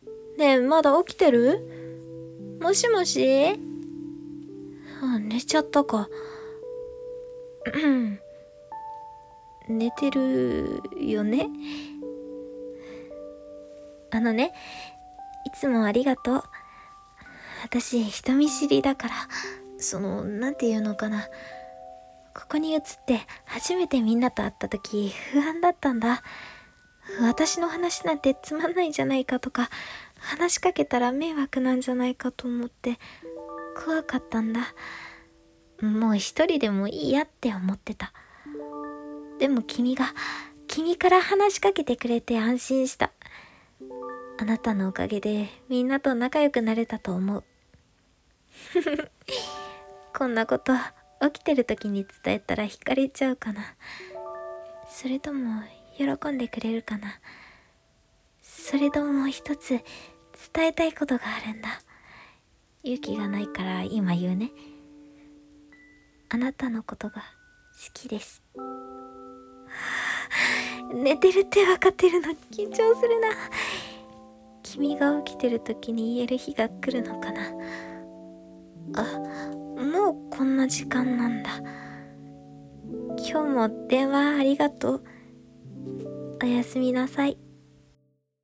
【1人声劇】 寝落ちしてる君へ